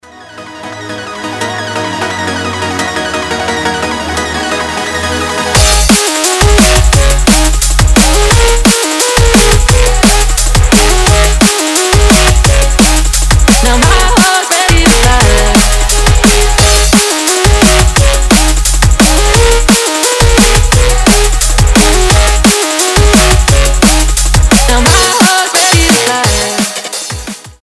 громкие
быстрые
драм энд бейс